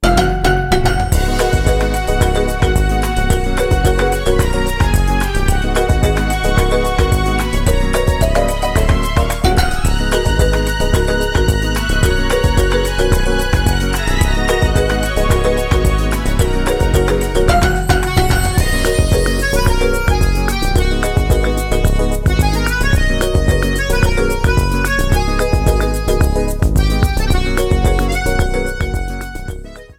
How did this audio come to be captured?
Ripped from the ISO Faded in the end